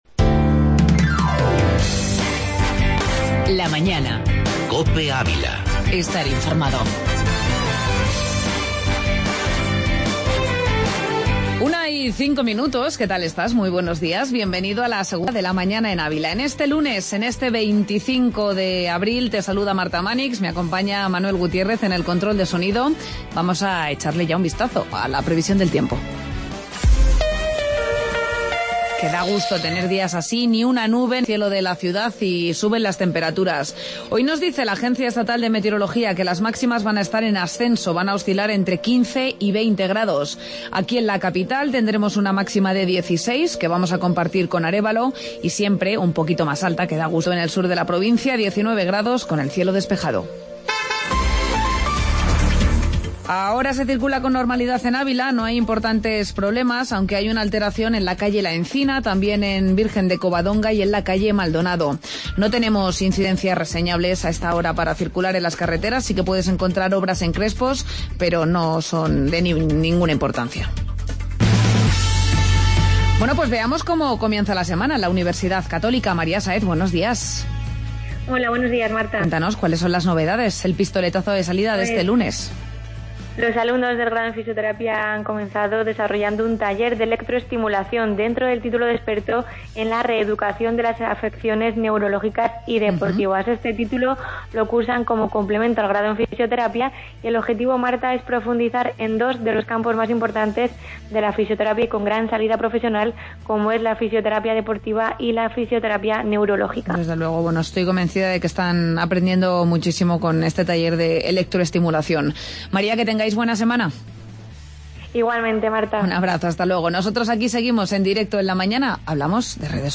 AUDIO: Entrevista Delegado de la Junta de Castilla y León en Ávila